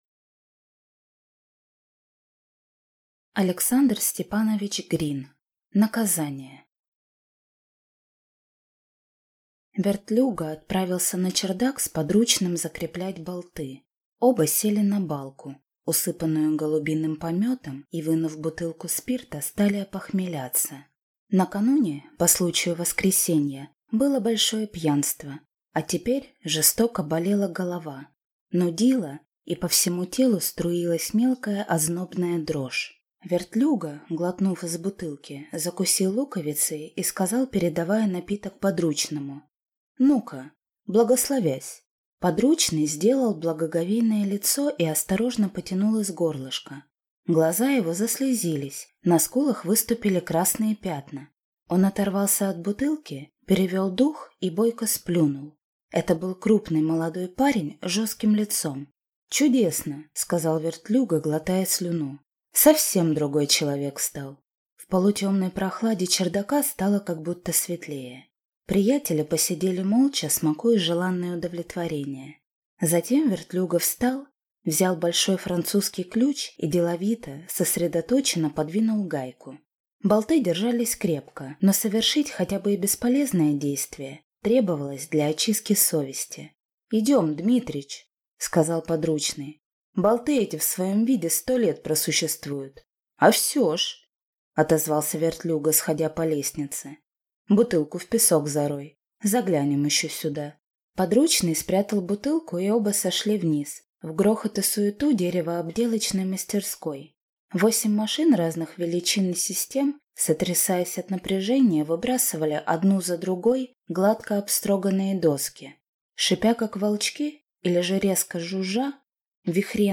Аудиокнига Наказание | Библиотека аудиокниг